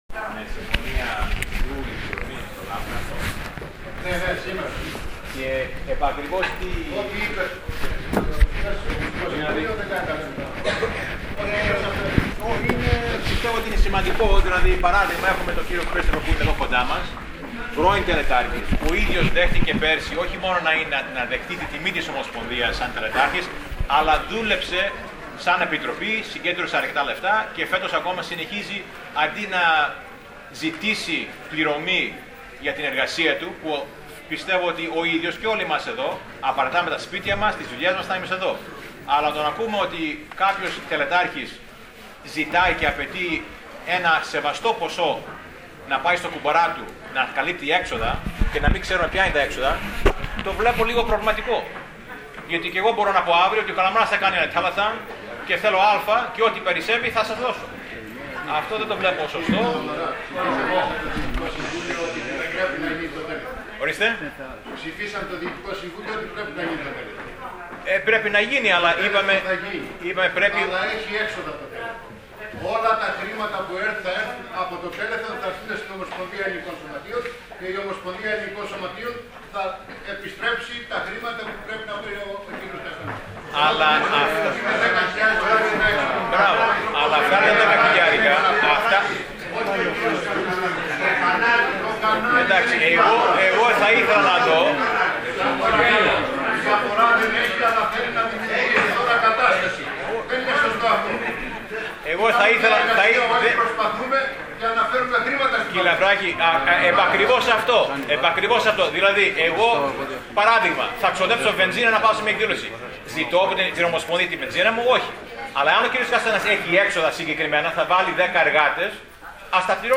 Ακούστε Live την πανομογενειακή συγκέντρωση ΜΕΡΟΣ Α ΜΕΡΟΣ Β πλήρες ρεπορτάζ ΣΕ ΛΙΓΟ...